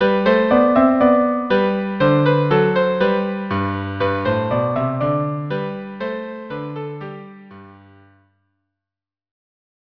traditional Hanukkah song
Instrumentation: Viola and Cello